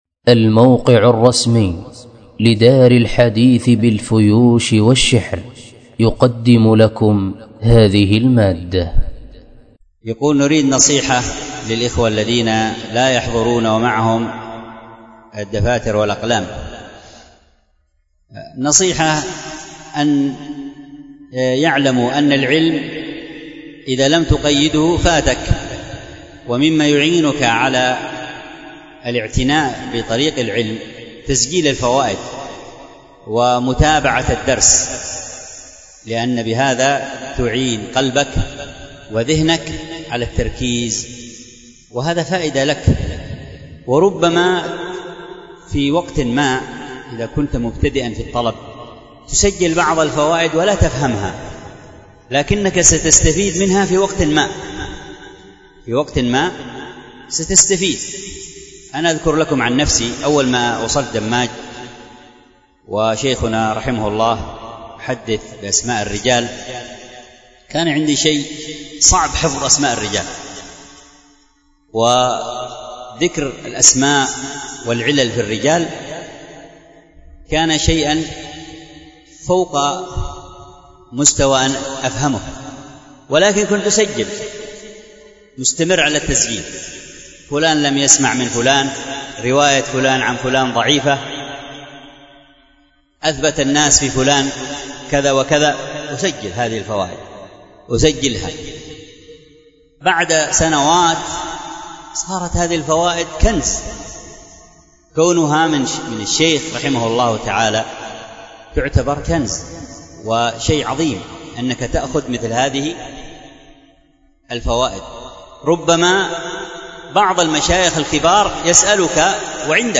الدرس في التقريرات السنية على المنظومة البيقونية 35، الدرس الخامس والثلاثون : الحديث المعضل .